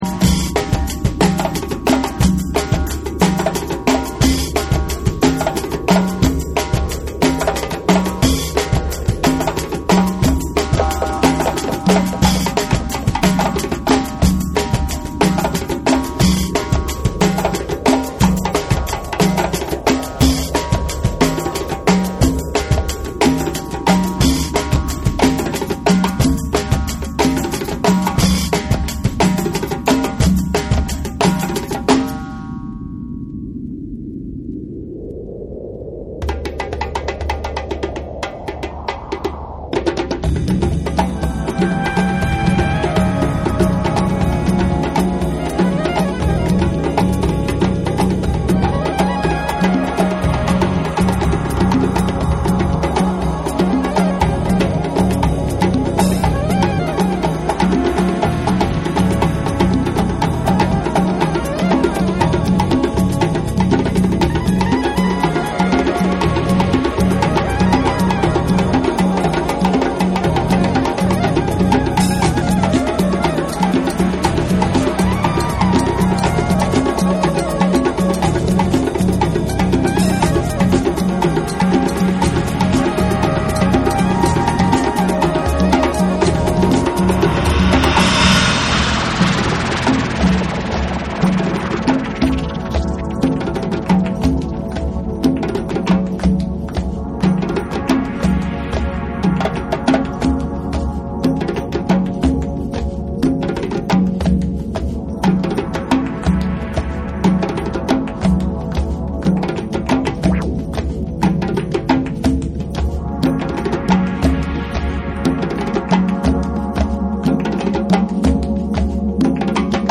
JAPANESE / ORGANIC GROOVE